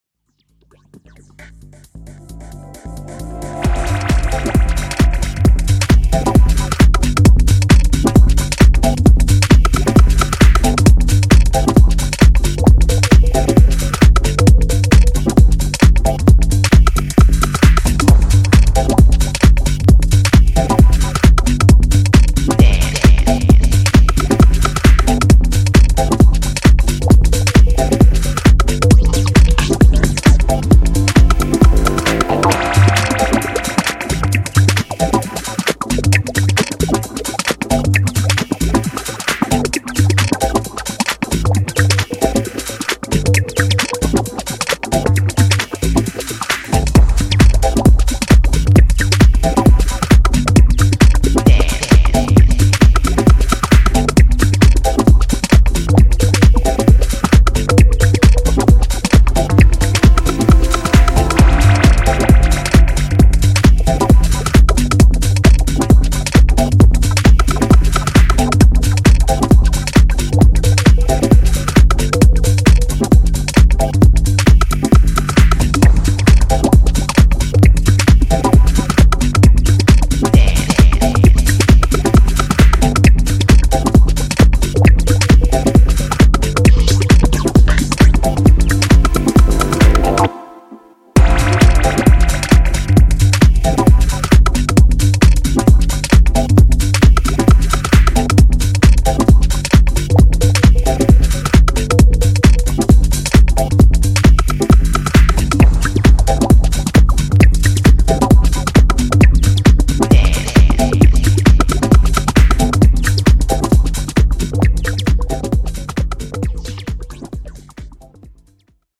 cool and sleek dusty house